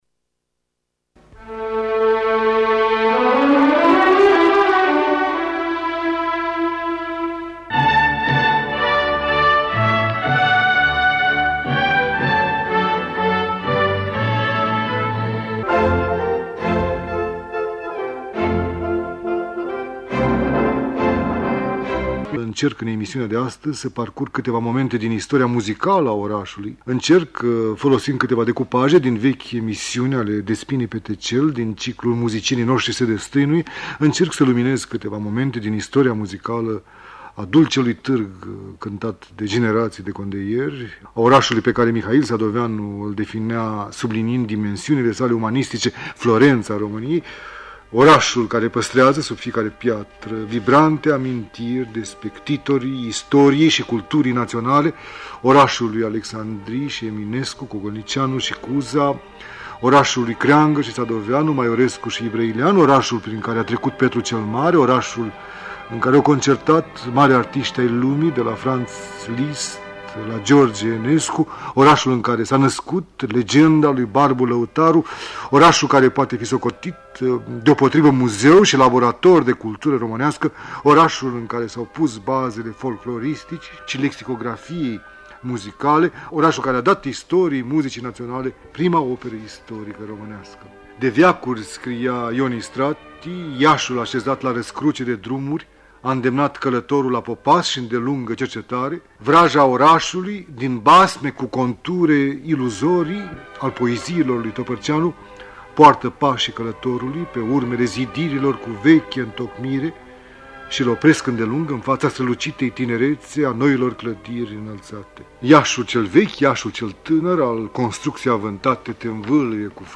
Fonoteca de Aur ne restituie, astăzi, vocea inegalabilă şi inconfundabilă a lui Iosif Sava.